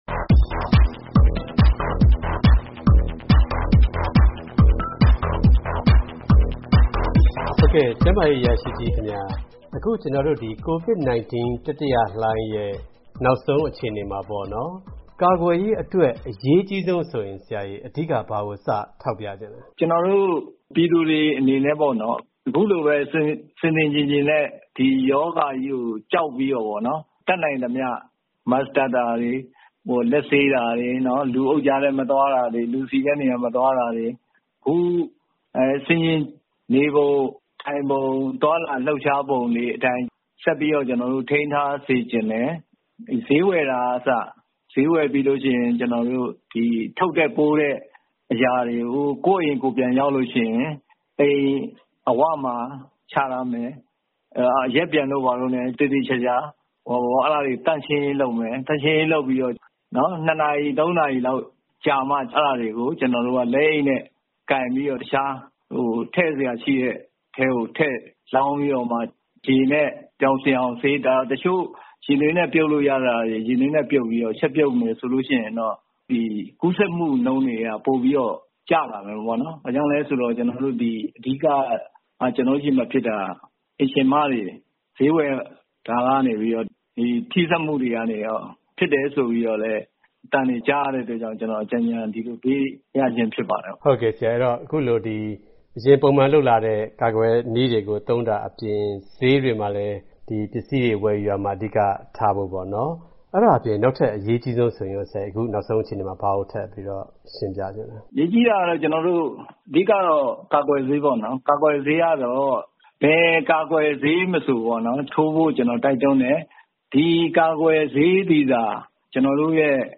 ရန်ကုန်မှာ ကိုဗစ်လူနာတွေကို ကိုင်တွယ်လုပ်ကိုင်ပေးနေတဲ့၊ လုံခြုံရေးကြောင့် အမည်မဖော်လိုသူ၊ ကျန်းမာရေးအရာရှိတစ်ဦးကို ဆက်သွယ်မေးထားပါတယ်။